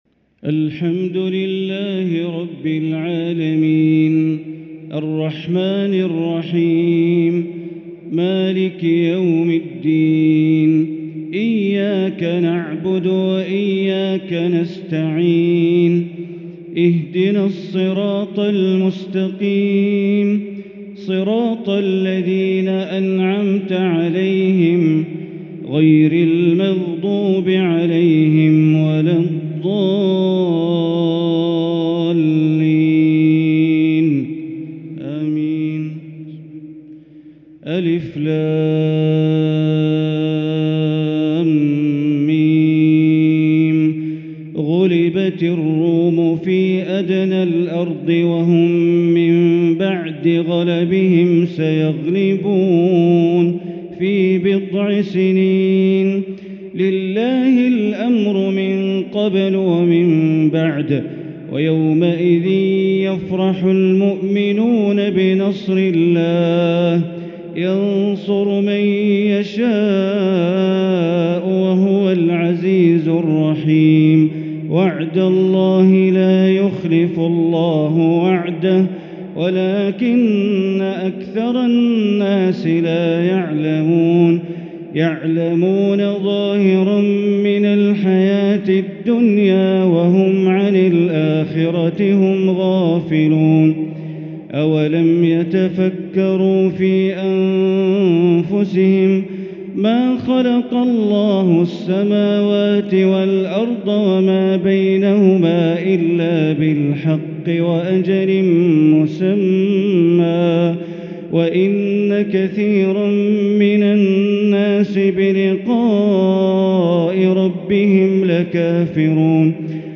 تهجد ليلة 29 رمضان 1444هـ سورة الروم كاملة | Tahajjud 29st night Ramadan 1444H Surah ar-Rum > تراويح الحرم المكي عام 1444 🕋 > التراويح - تلاوات الحرمين